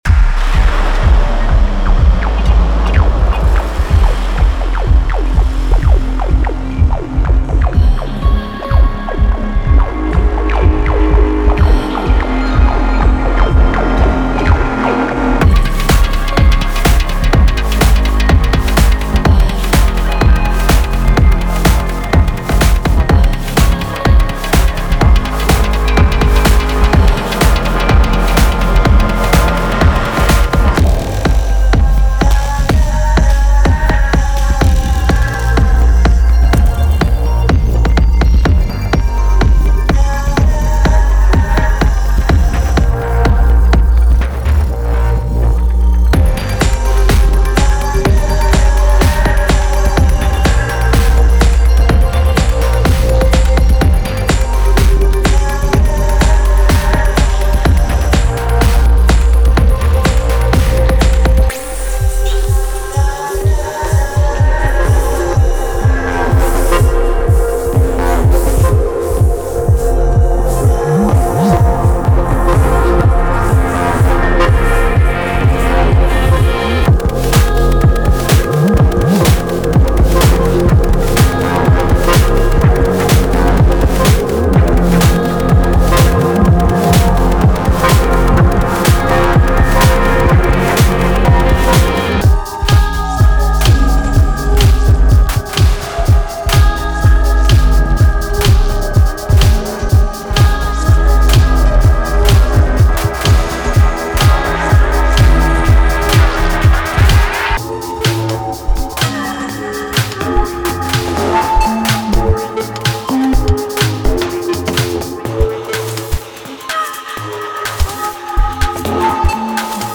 Genre:Melodic Techno
アグレッシブで強烈なディストーションがかかったサウンドから、メロディックで神秘的なものまで多彩です。
デモサウンドはコチラ↓
140 Music loops